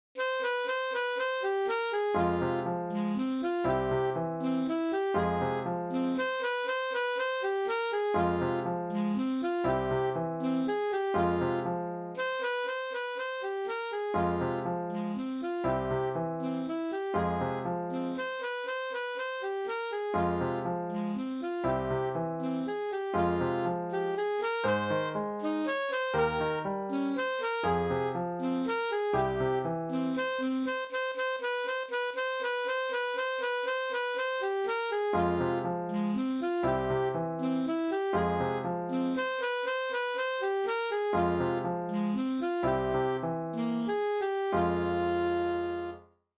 Easy Alto Saxophone Solo with Piano Accompaniment